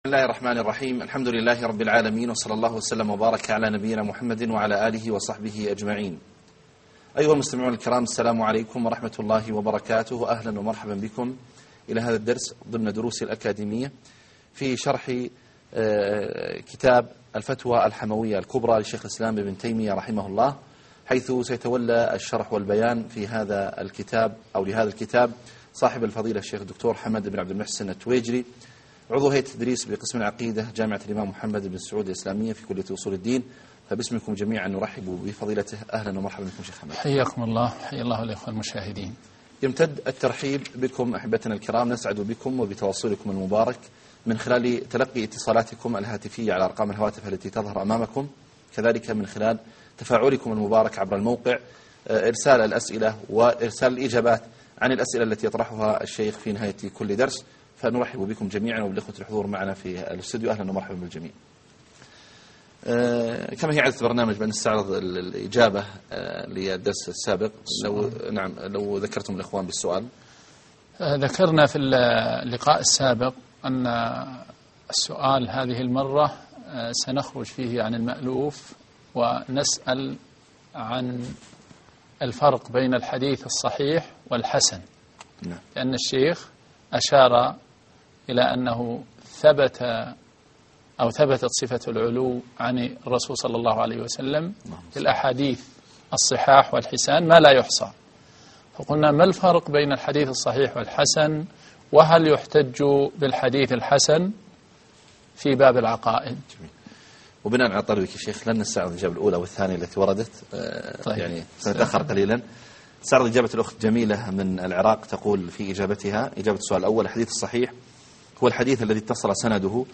الدرس 8 _ منهج نفاة الصفات ومصادر شبهاتهم